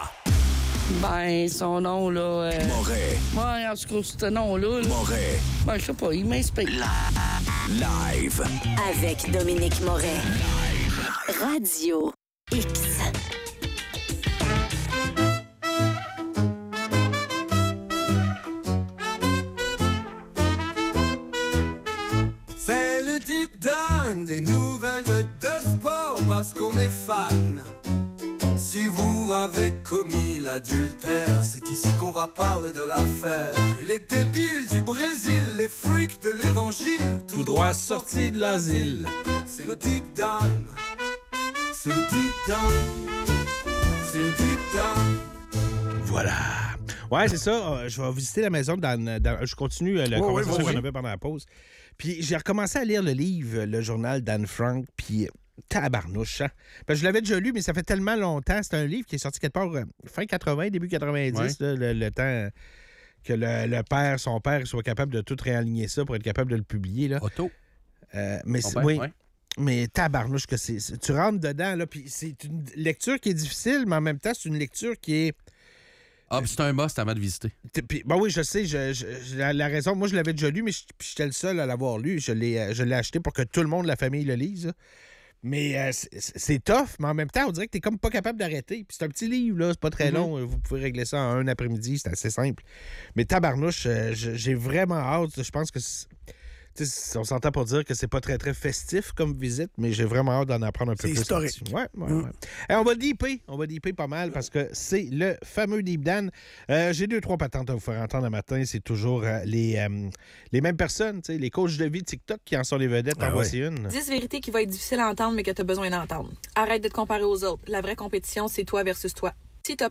La quête d'un restaurant abordable pour la Saint-Valentin devient un véritable casse-tête pour une auditrice, qui se plaint des prix exorbitants et du manque de choix à Montréal. À travers des anecdotes humoristiques, l'épisode aborde aussi les attentes des jeunes couples et la pression sociale entourant cette fête.